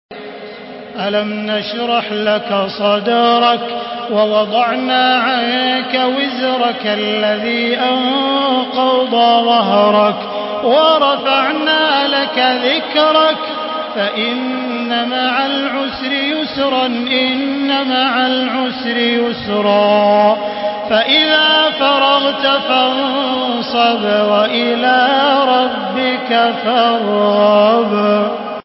Surah আশ-শারহ MP3 in the Voice of Makkah Taraweeh 1435 in Hafs Narration
Surah আশ-শারহ MP3 by Makkah Taraweeh 1435 in Hafs An Asim narration.